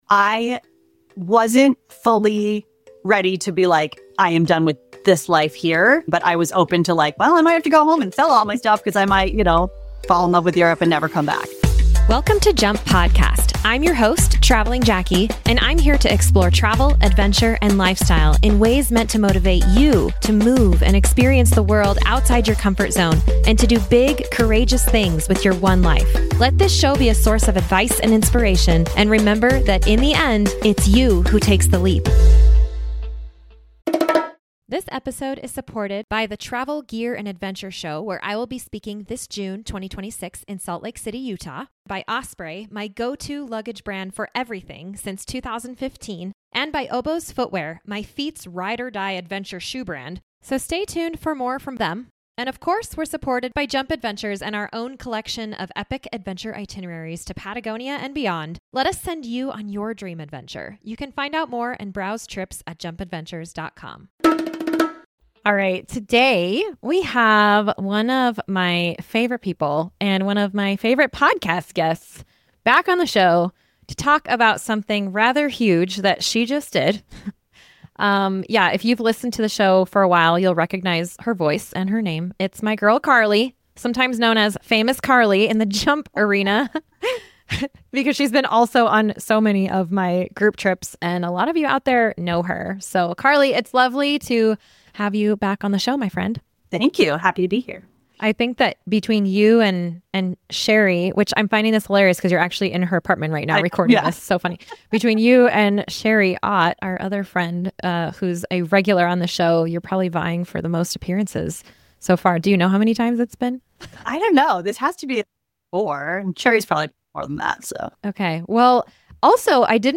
1 817 Budget Travel Tips; Moonlight Express; Hola South America 52:00 Play Pause 1m ago 52:00 Play Pause Play later Play later Lists Like Liked 52:00 A budget-minded travel blogger shares timely tips for keeping costs down — so that you can keep your travel dreams alive. Then a British journalist with a passion for rail travel enthuses about the special magic of the sleeper train. And an American writer based in Chile tells us what he loves about living in South America.